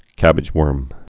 (kăbĭj-wûrm)